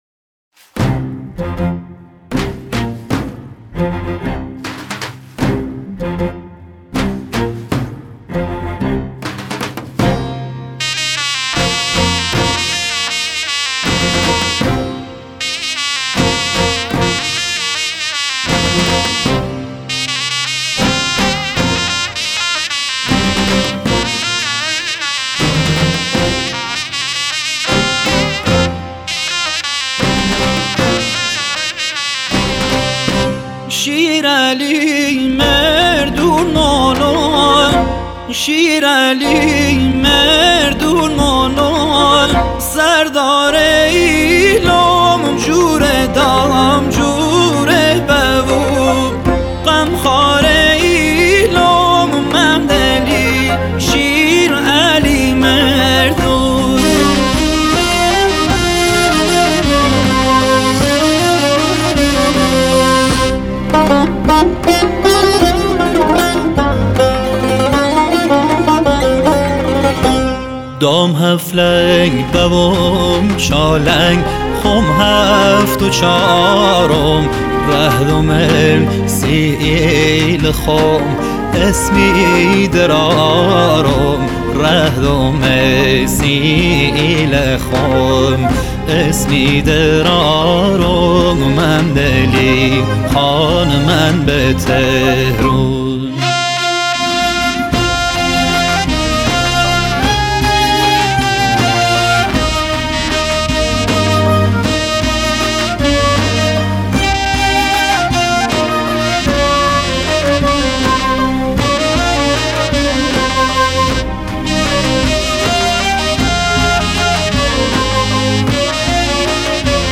آهنگ بختیاری